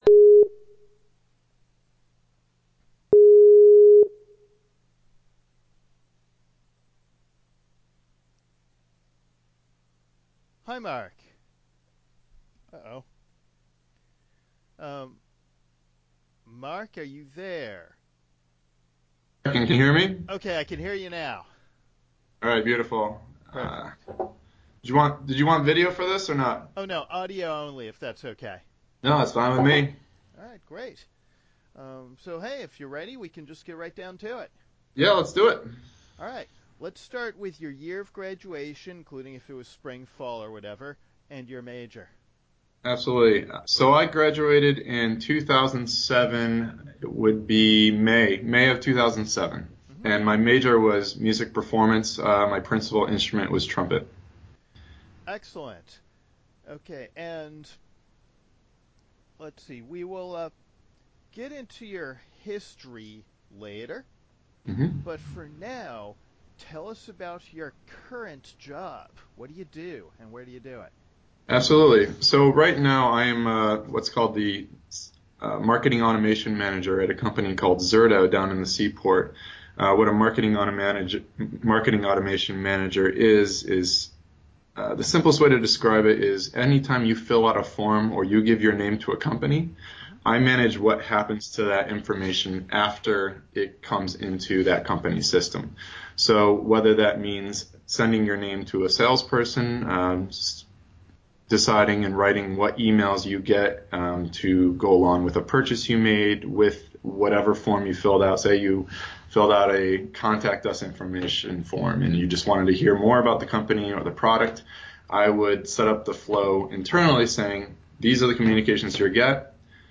Interviews with Berklee graduates working in fields not related to music.